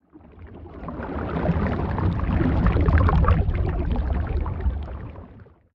Sfx_creature_glowwhale_swim_slow_05.ogg